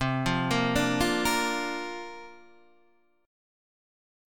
C 9th Suspended 4th